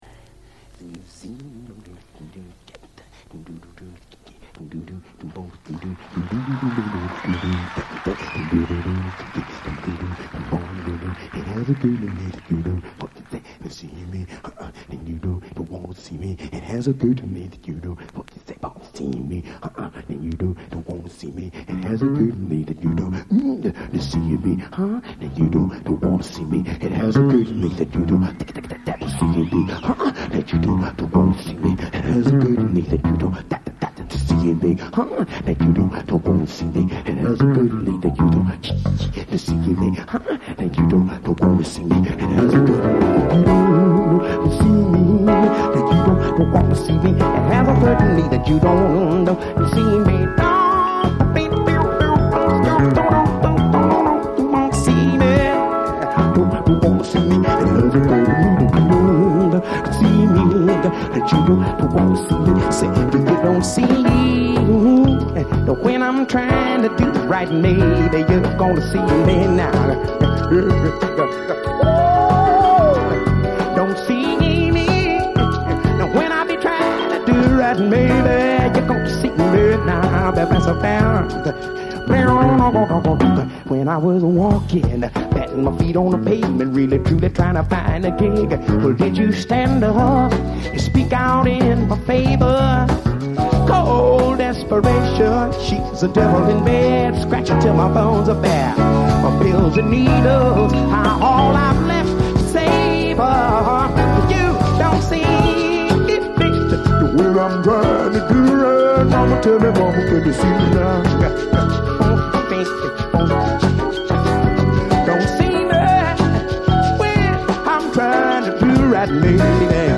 dal vivo.